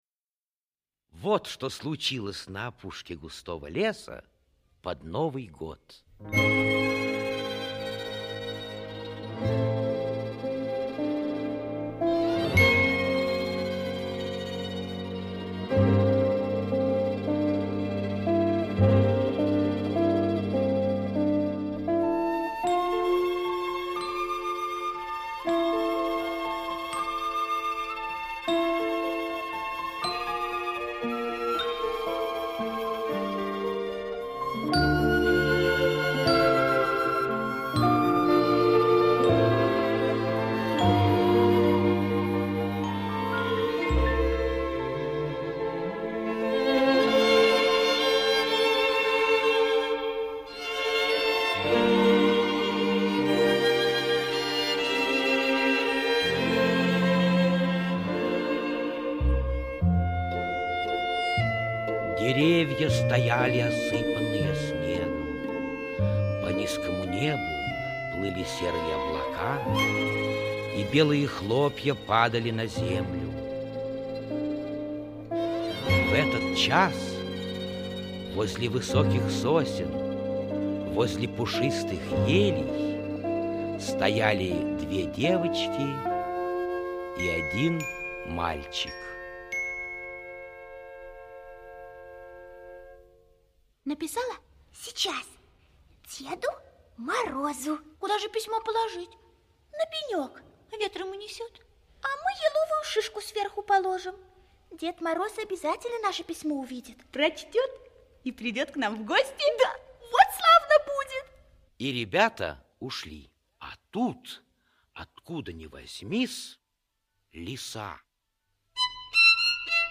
Под Новый Год - аудиосказка Синельникова - слушать онлайн